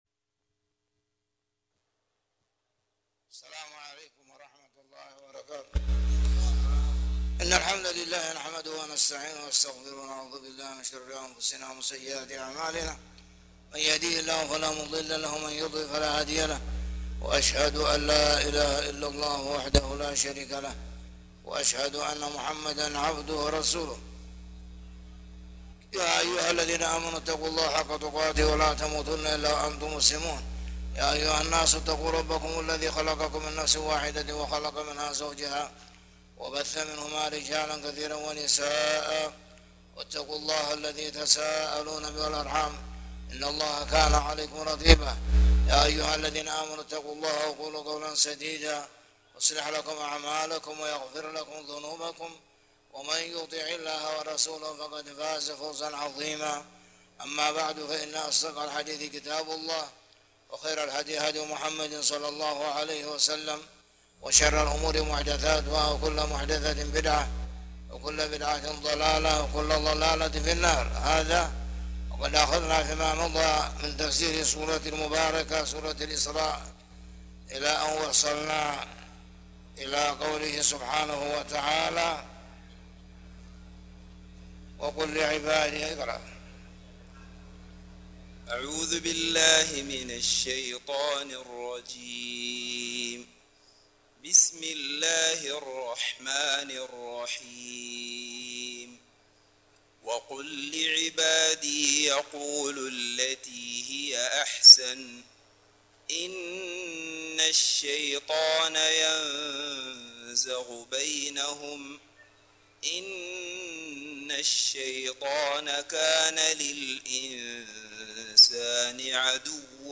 تاريخ النشر ٥ جمادى الأولى ١٤٣٨ هـ المكان: المسجد الحرام الشيخ